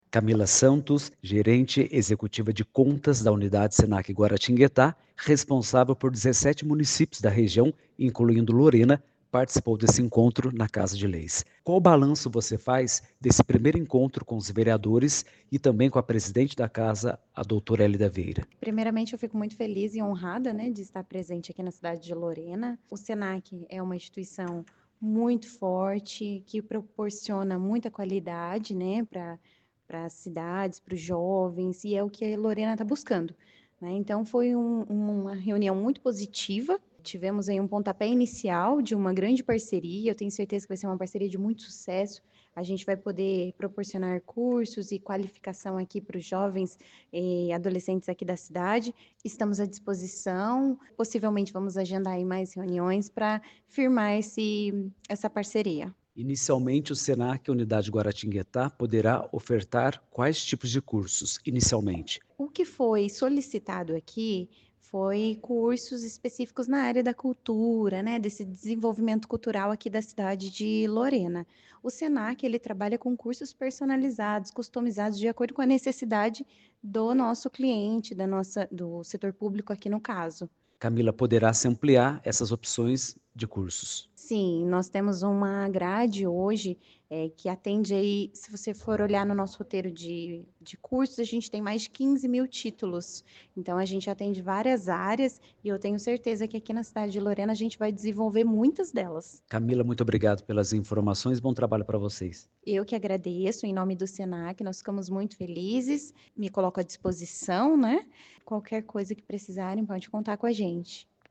Entrevistas (áudios):